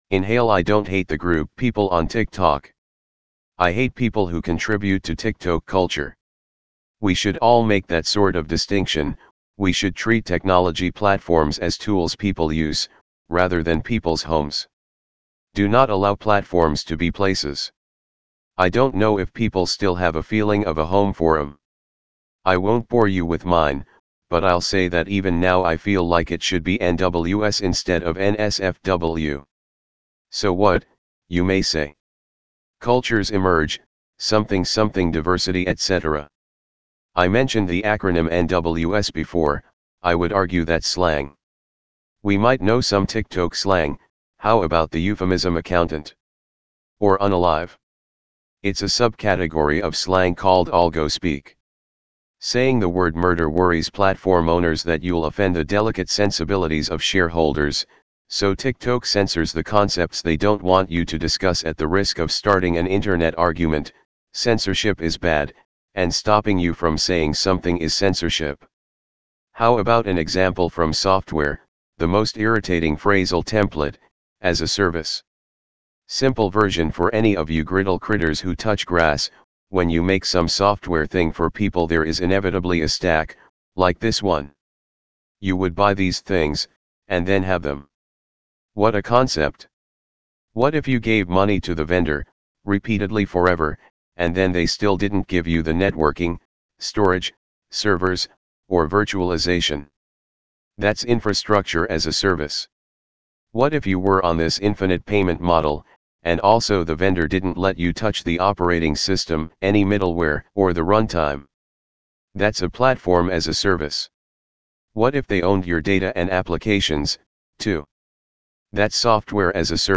primaryVO.wav